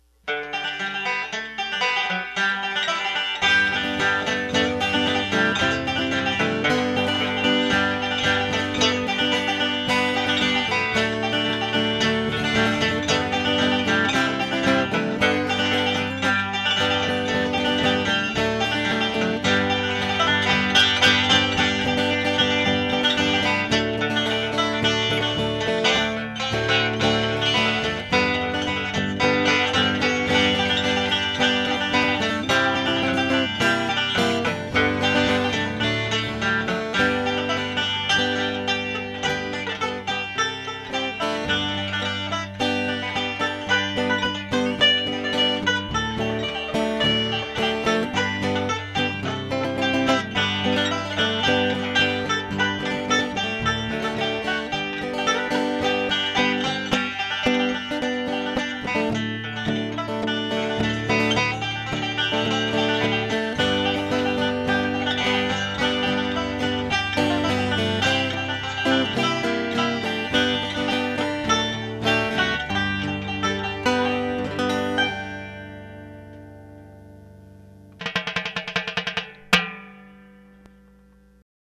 He's on his banjo, and I'm on my guitar. We're both going through electronic pickups, so it's not quite natural.